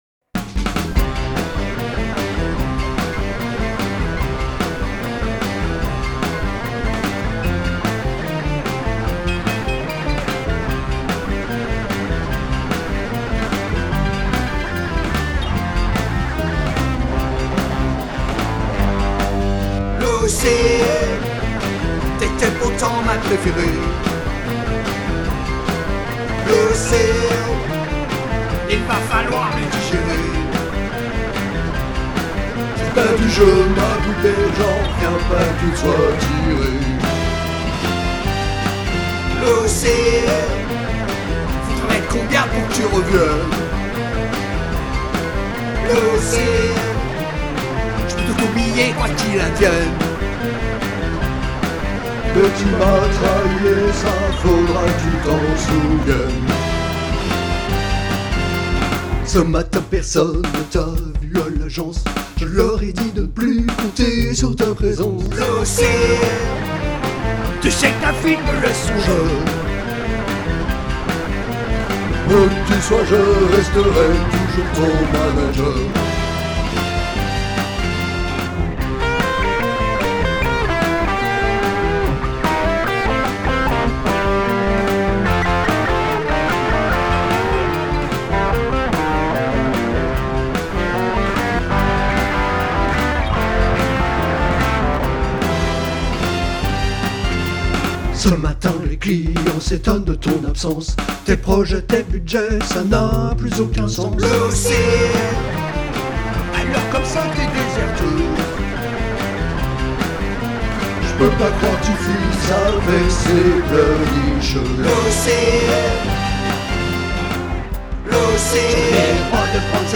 composition et piano